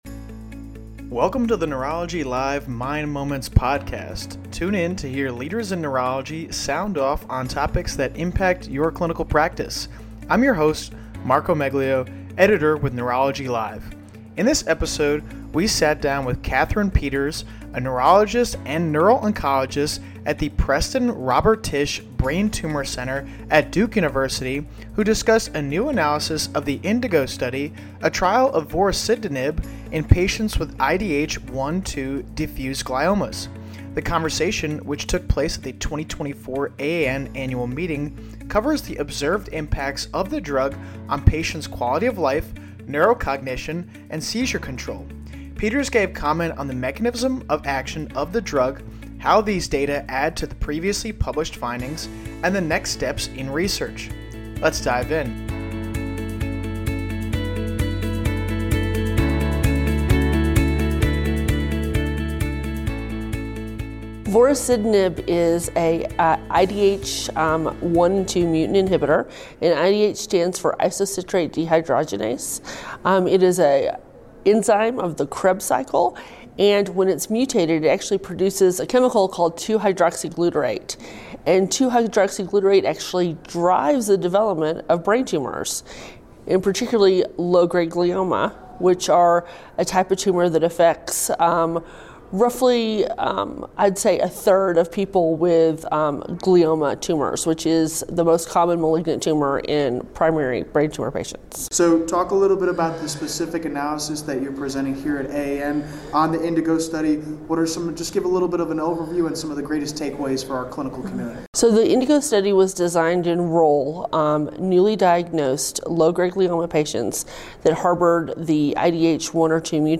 Tune in to hear leaders in neurology sound off on topics that impact your clinical practice.
The conversation, which occurred at the 2024 AAN Annual Meeting, covered the covered the therapeutic potential of this agent and its impacts on quality of life, neurocognition, and seizure control.